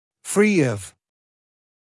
[friː ɔv][фриː ов]свободный от, не имеющий (чего-то)